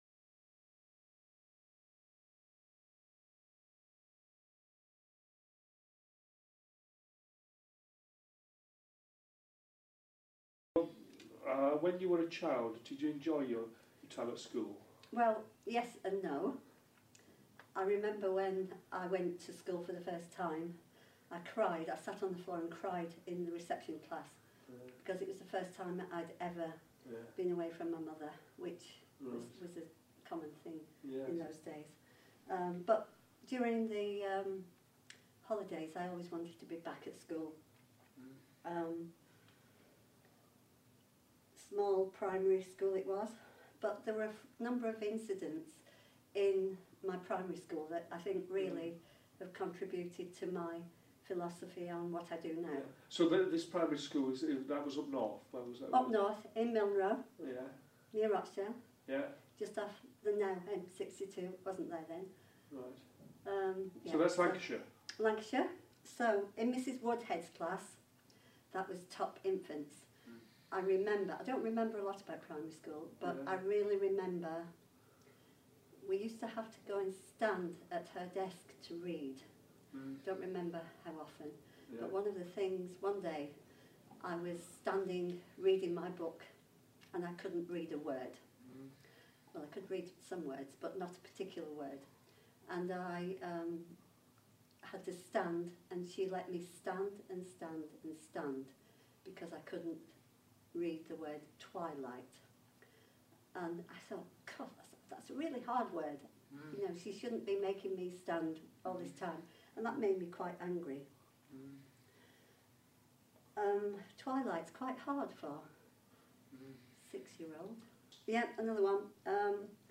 Part of a series of interviews with veteran teachers and teacher educators